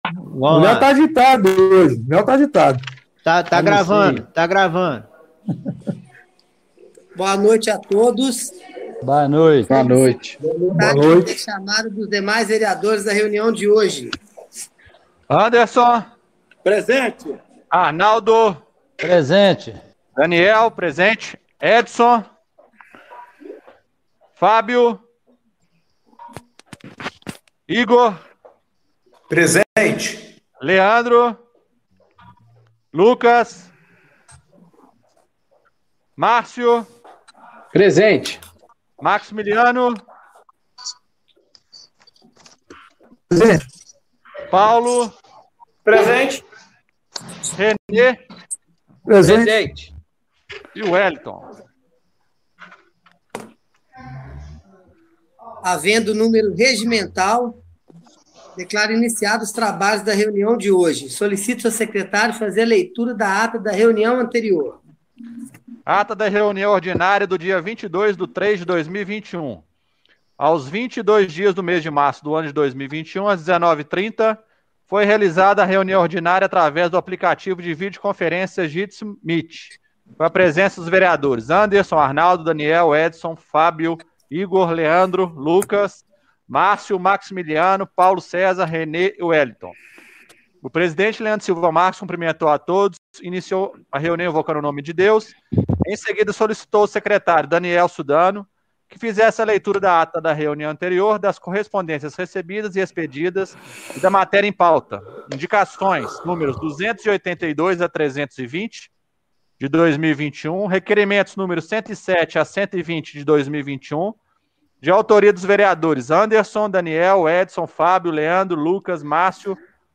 Reunião Extraordinária do dia 24/03/2021